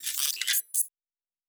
pgs/Assets/Audio/Sci-Fi Sounds/Weapons/Additional Weapon Sounds 5_4.wav at master
Additional Weapon Sounds 5_4.wav